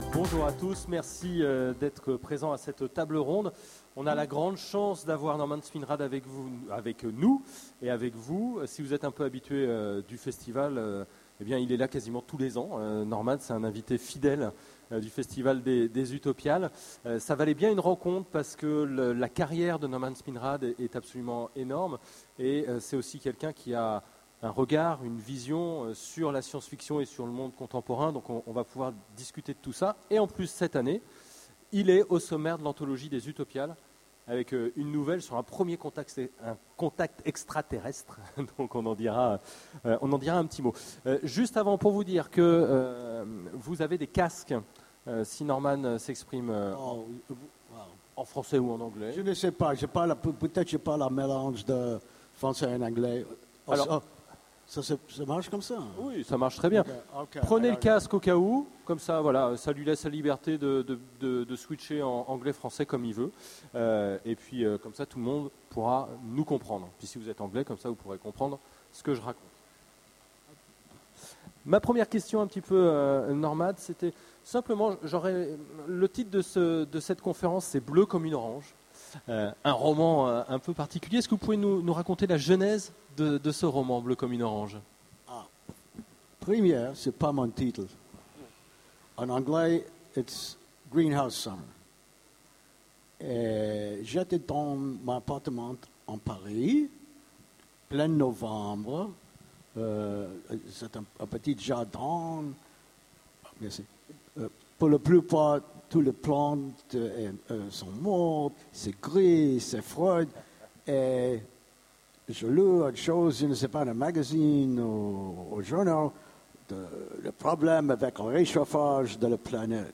- le 31/10/2017 Partager Commenter Utopiales 13 : Conférence Bleue comme une orange Télécharger le MP3 à lire aussi Norman Spinrad Genres / Mots-clés Rencontre avec un auteur Conférence Partager cet article